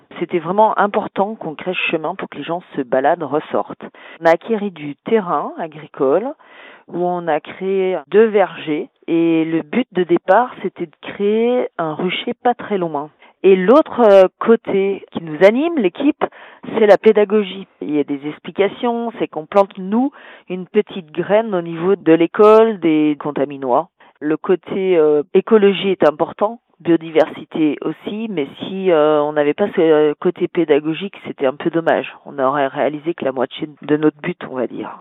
L’idée est en fait partie de la mise en place du chemin qui traverse la commune depuis le chef-lieu jusqu’au château comme l’explique Aline Watt-Chevalier la mairesse de Contamine-sur-Arve.